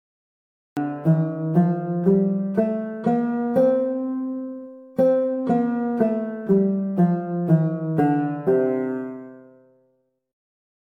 Arabic-scale_rast.mp3